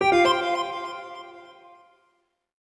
ArrivedDestination.wav